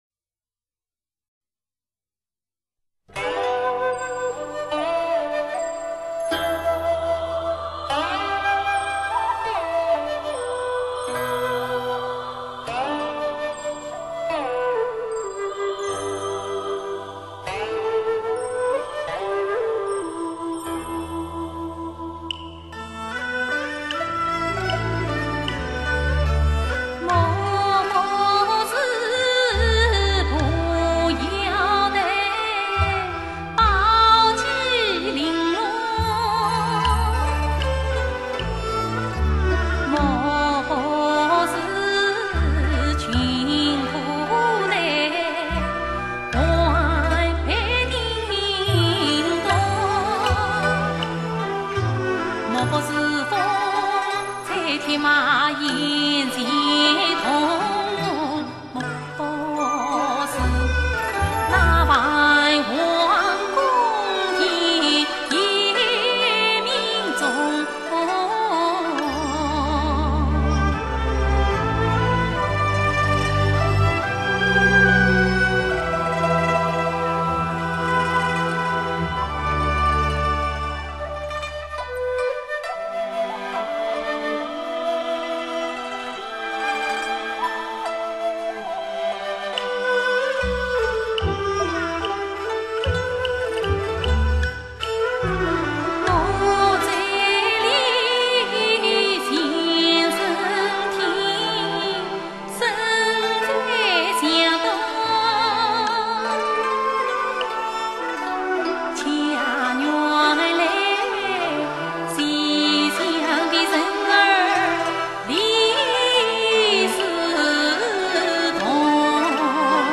她的唱腔委婉细腻，深沉流畅，韵味醇厚，嗓音清亮，乃同代越剧花旦中的佼佼者。